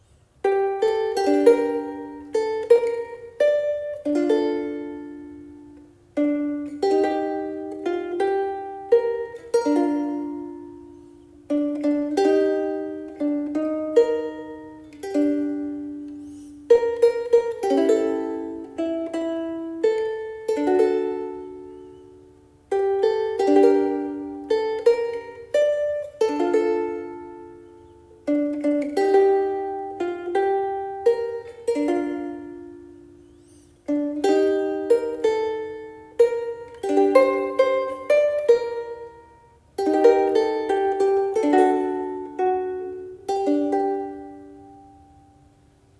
コンサートサイズロングネック(弦長テナー)のウクレレ。
今回、その両方を合わせてみて、期待を裏切らない音になりました。
軽快な音色です。